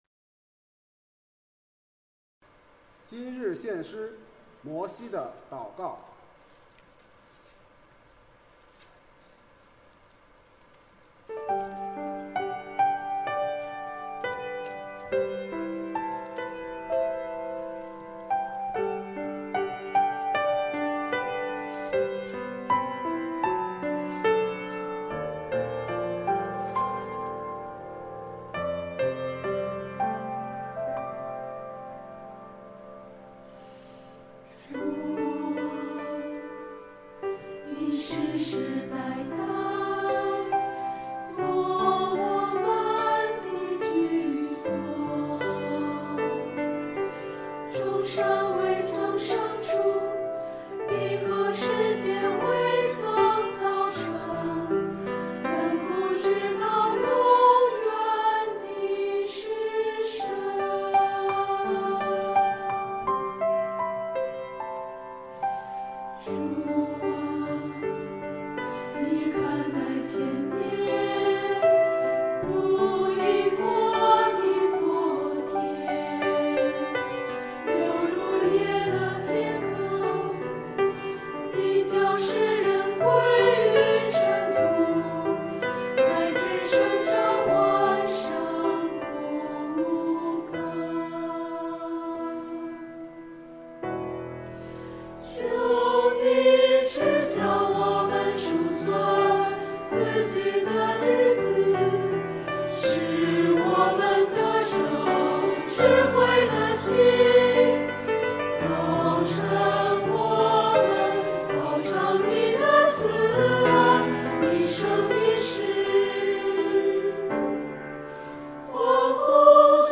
团契名称: 清泉诗班 新闻分类: 诗班献诗